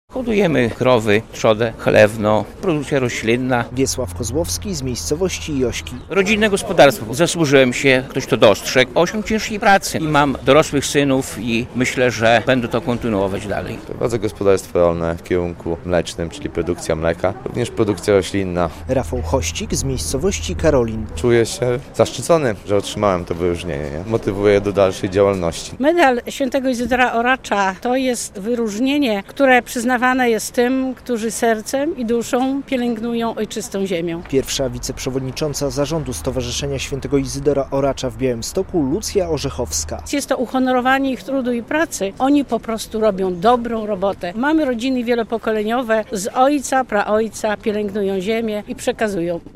Uroczystość - już po raz 21. - odbyła się w Kościele pw. Ducha Świętego w Białymstoku.